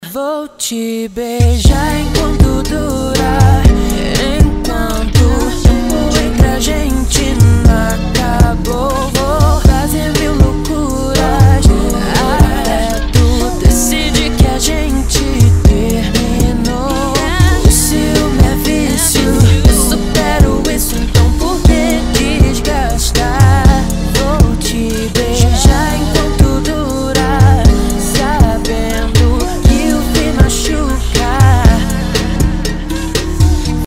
• Качество: 320, Stereo
спокойные